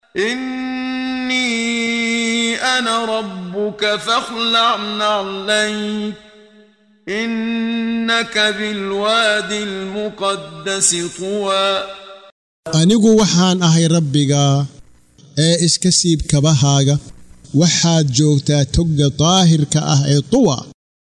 Waa Akhrin Codeed Af Soomaali ah ee Macaanida Suuradda Ta Ha oo u kala Qaybsan Aayado ahaan ayna la Socoto Akhrinta Qaariga Sheekh Muxammad Siddiiq Al-Manshaawi.